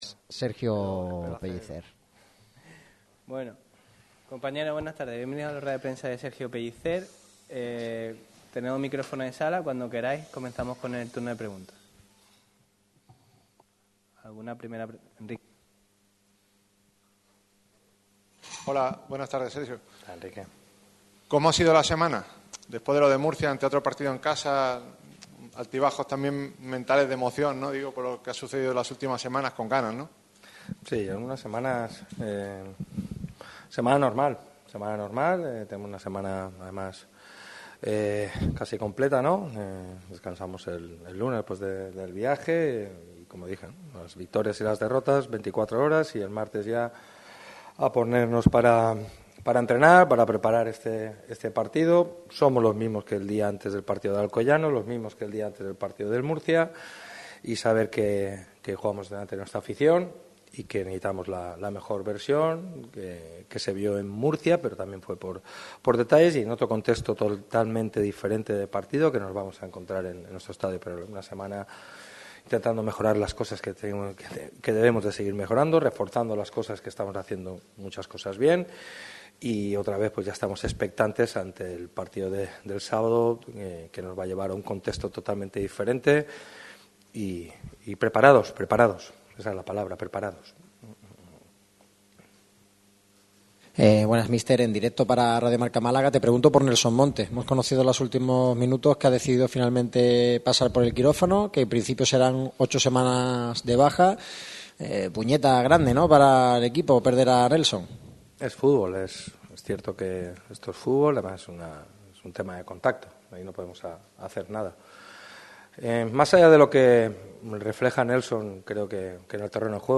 El técnico de Nules ha comparecido ante los medios con motivo de la previa del choque que enfrentará a los boquerones contra el Atlético Sanluqueño este sábado a las 20:00 horas. El míster repasa el estado del equipo, las bajas con las que contará el Málaga CF y cómo afronta el partido.